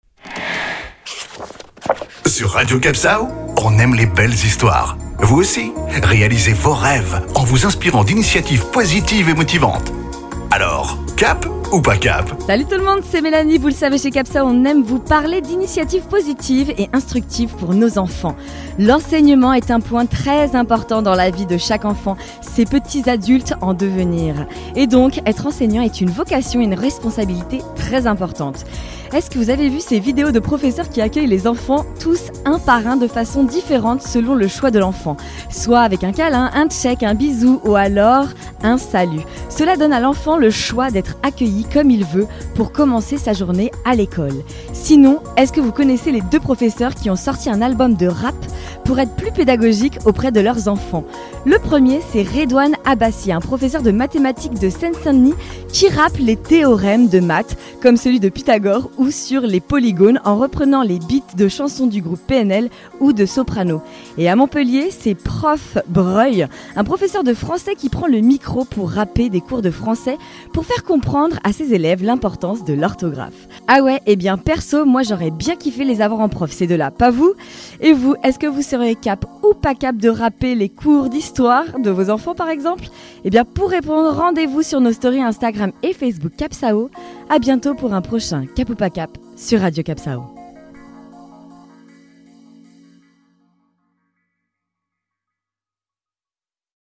Cap ou pas cap ? Le rap des leçons à l'école